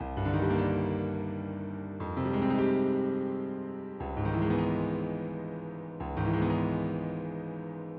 寒冷的Hip Hop钢琴在120 BPM
描述：在Fl工作室制作的，我使用了一架三角钢琴，并将和弦的进展堆积起来。
标签： 120 bpm Hip Hop Loops Piano Loops 1.35 MB wav Key : Unknown
声道立体声